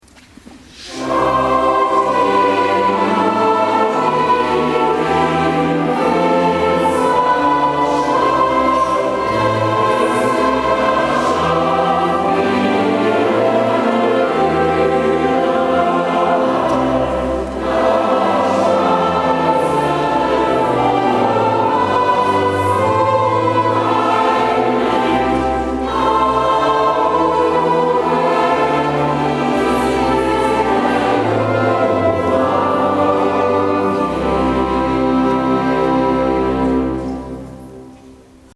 Trotz Schneebruch und Glättewarnung fanden sich letzten Samstag gut 500 gespannte Zuhörer in der Sankt Meinrads- Kirche in Radolfzell ein.
Mit dem ersten präsenten Choreinsatz der 65 Choristen „Jauchzet, Frohlocket“ wurde klar, der Chor war bestens vorbereitet, agierte präzise und mit rundem und ausgewogenem Klang.
Weiche, tänzerische Klänge der vier Oboisten
mit tiefem Alt
Der samtweiche lyrische Bariton
Das Gesamtwerk quittierten das Publikum mit langanhaltendem Applaus und Standing Ovations.
Pauken und Trompeten erklangen und es wurde ganz still in der Kirche.
Vorweihnachtliche und festliche Klänge zur passenden Zeit.